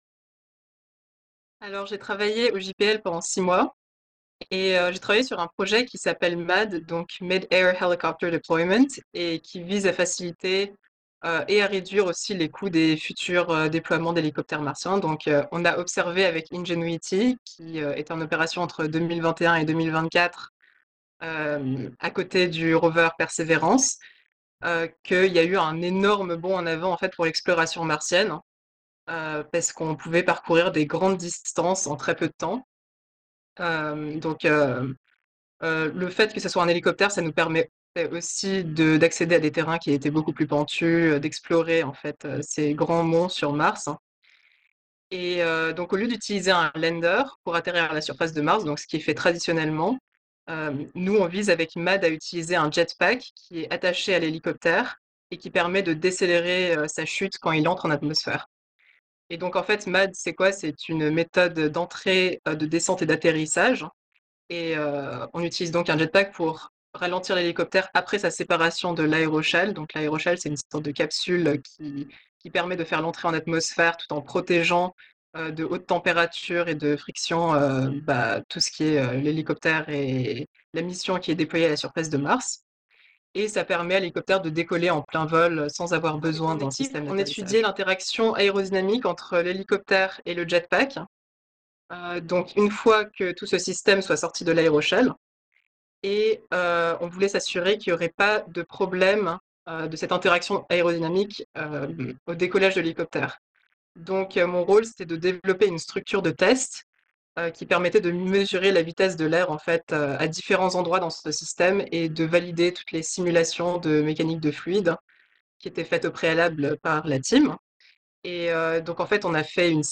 Petit écho de son stage en podcast :